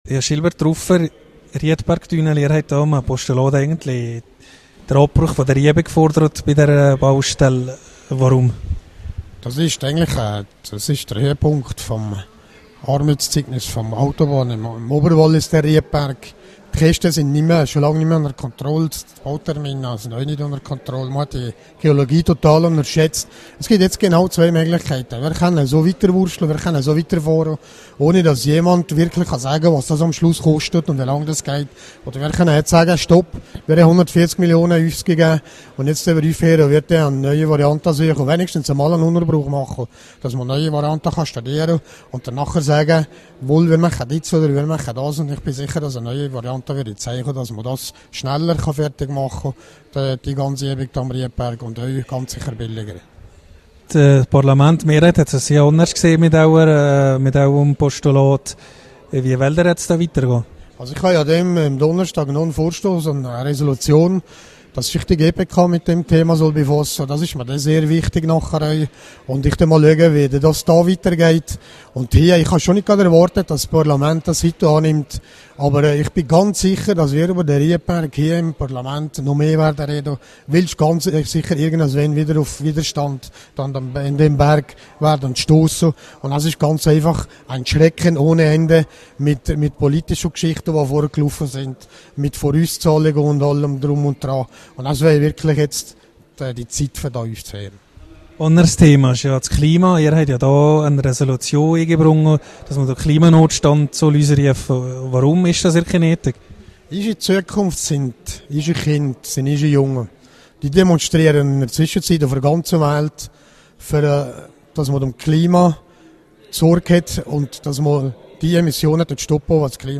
Rückblick auf den zweiten Tag der Märzsession mit SVPO-Fraktionschef Michael Graber.